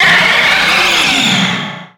Cri de Méga-Mewtwo Y dans Pokémon X et Y.
Cri_0150_Méga_Y_XY.ogg